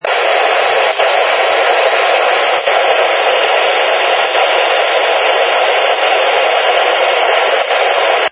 Ils ont été effectués à l'aide d'un camescope placé près du haut-parleur d'un MVT-7100.
Enregistrement 5 : NFM. La coupure du souffle est d'autant plus nette que le signal est fort.
On voit que les tops ont une durée de 0,08 seconde et qu'ils sont émis toutes les 1,7secondes.